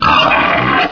pain2.ogg